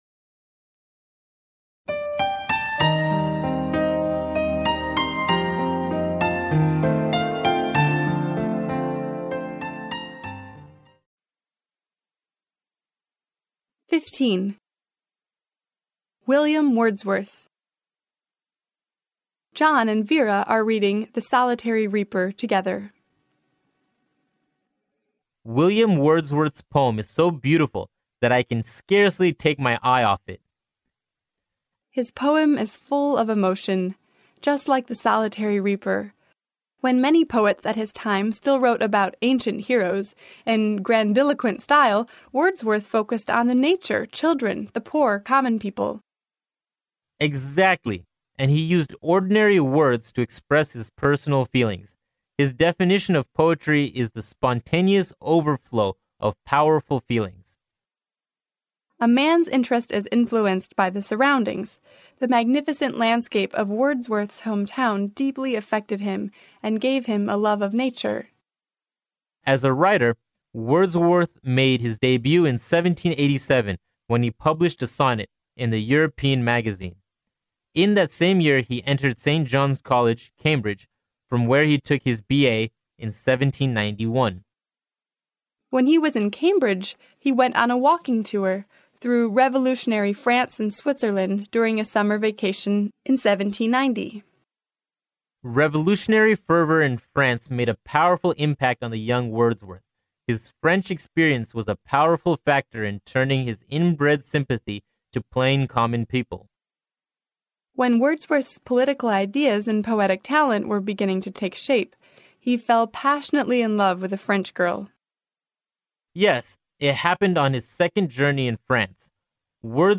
剑桥大学校园英语情景对话15：湖畔诗人华玆华斯（mp3+中英）